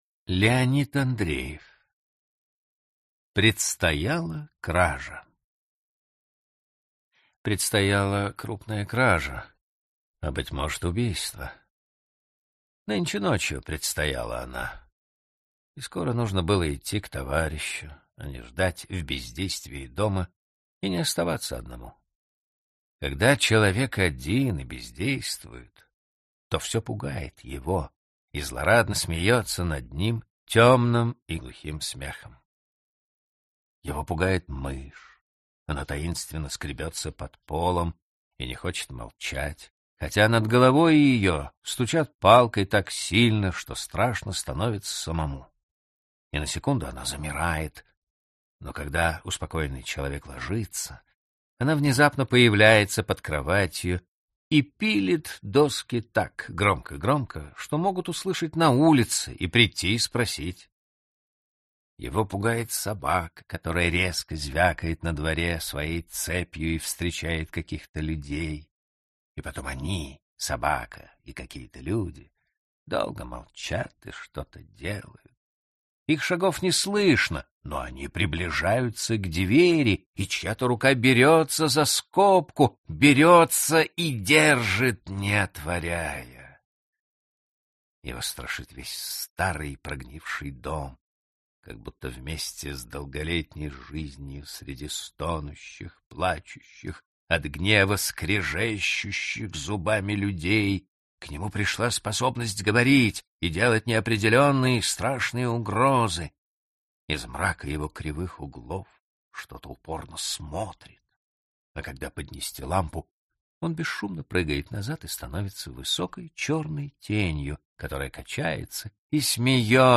Аудиокнига Классика русского рассказа № 7 | Библиотека аудиокниг